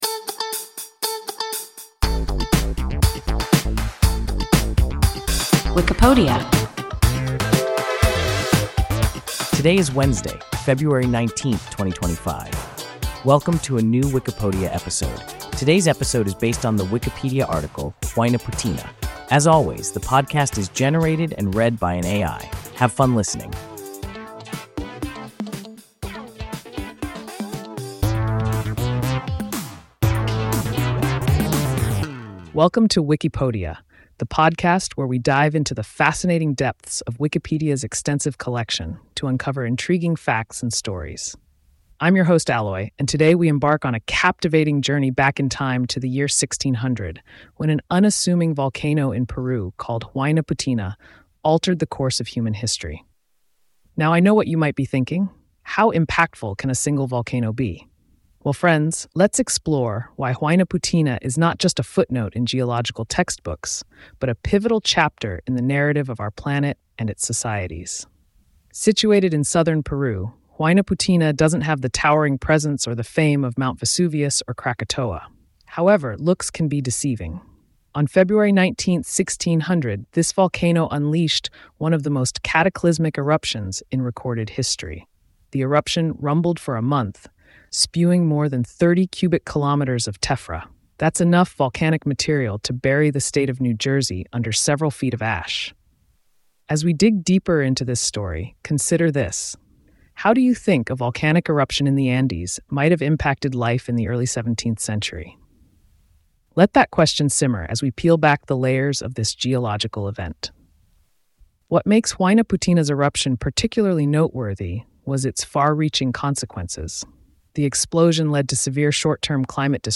Huaynaputina – WIKIPODIA – ein KI Podcast